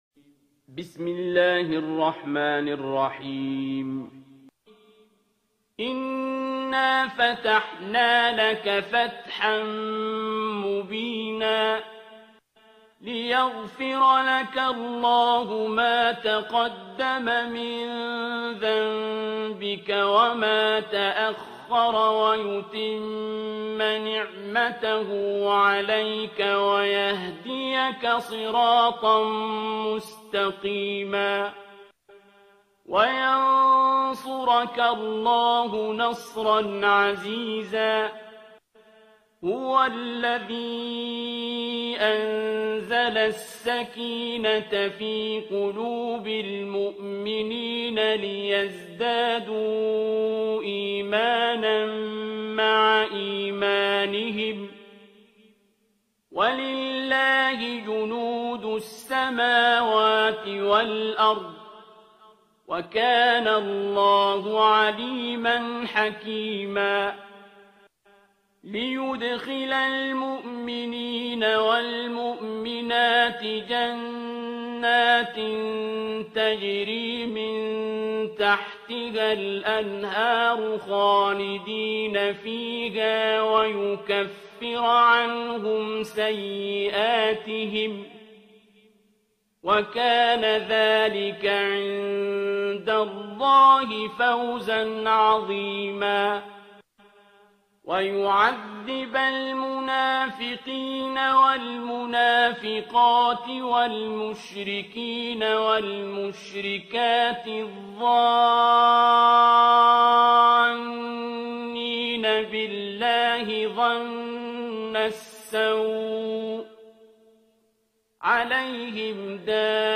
ترتیل سوره فتح با صدای عبدالباسط عبدالصمد